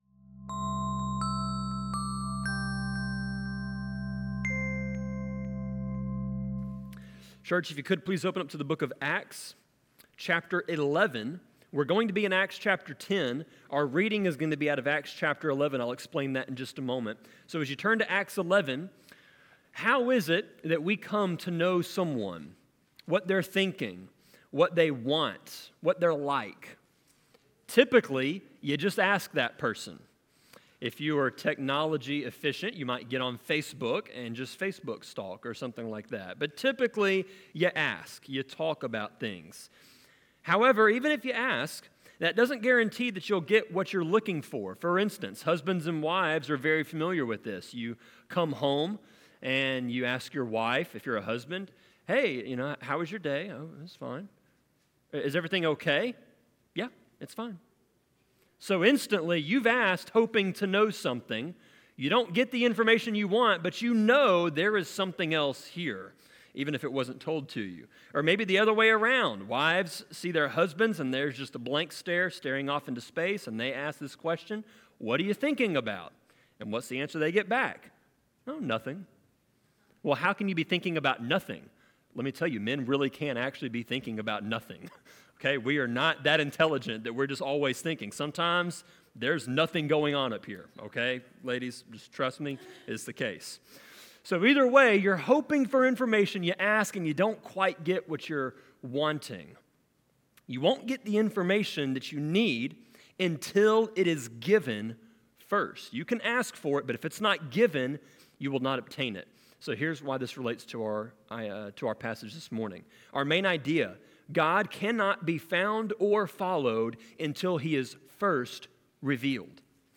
Sermon-24.5.5.m4a